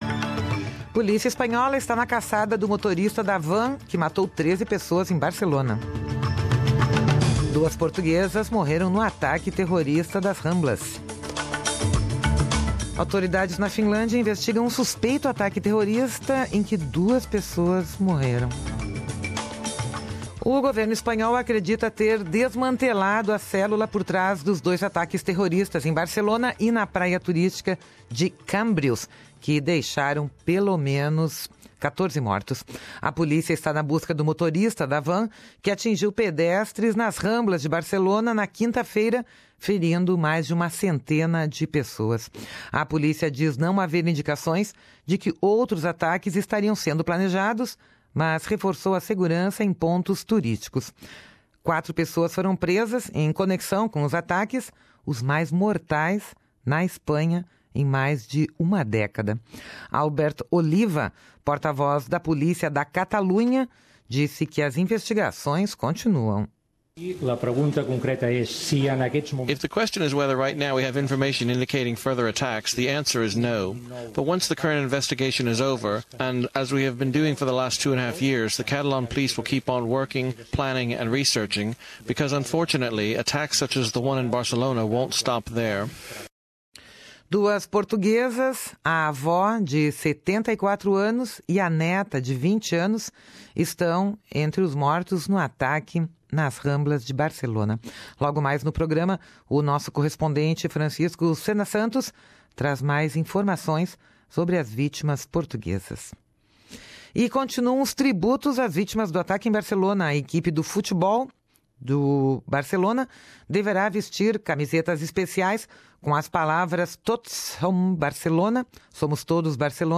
Ouça aqui a reportagem do nosso correspondente